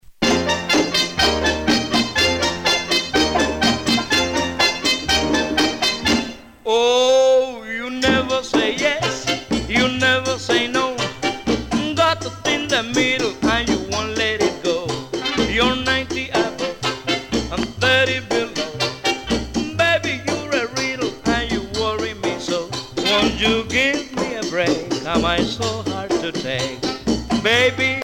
danse : mambo